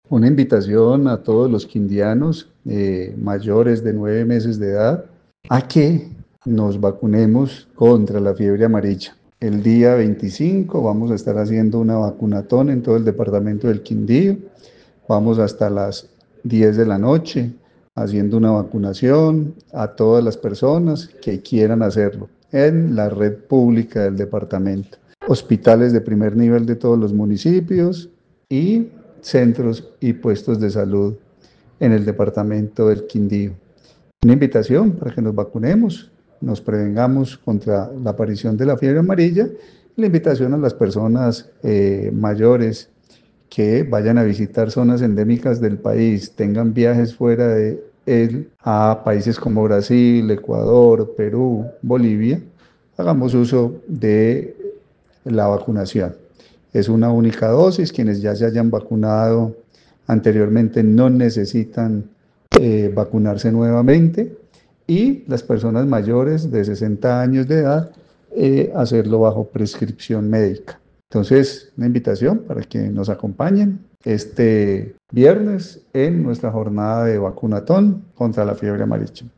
Secretario de Salud del Quindío